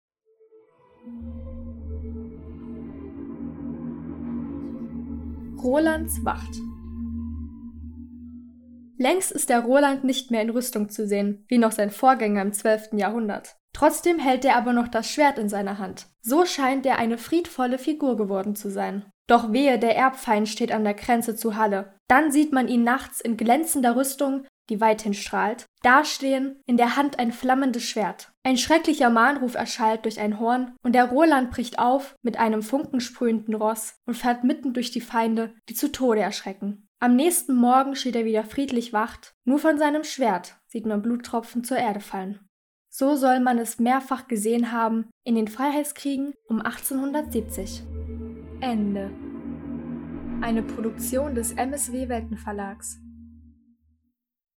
Eine Sage aus Halle (Saale) vorgelesen von der Salzmagd des